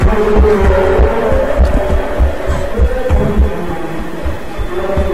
Sound Buttons: Sound Buttons View : Monster Noise
Monster-Noise.mp3